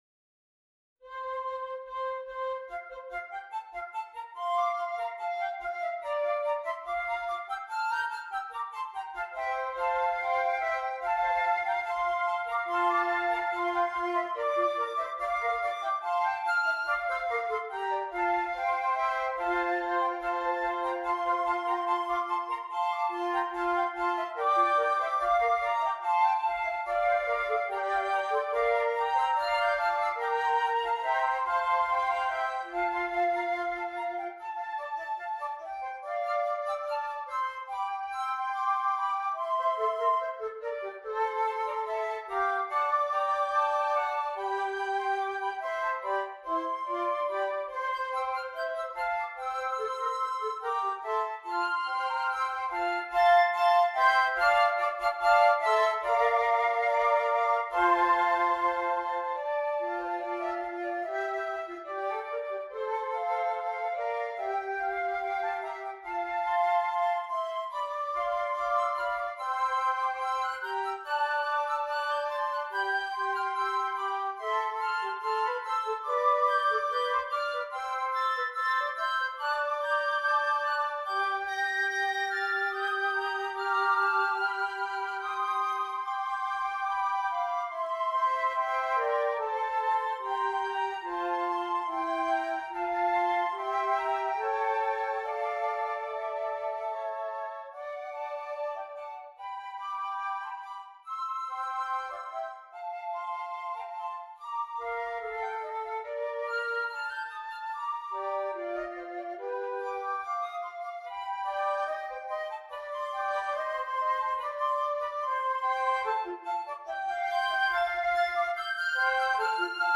6 Flutes